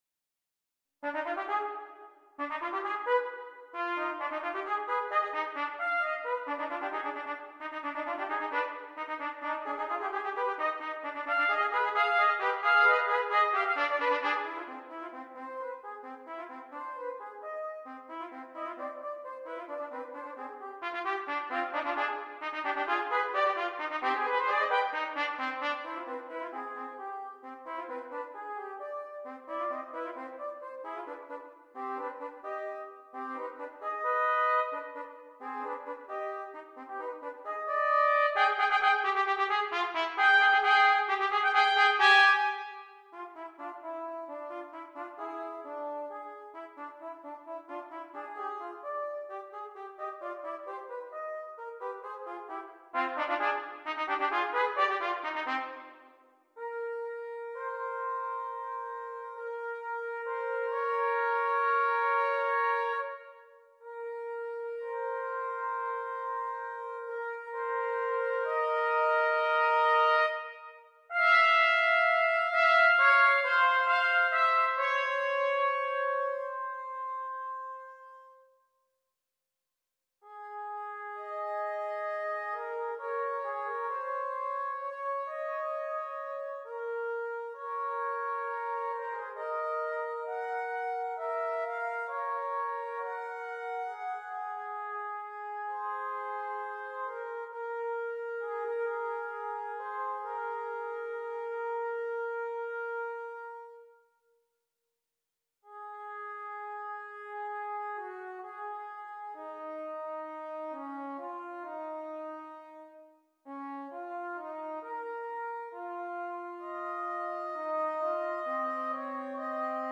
2 Trumpets
duet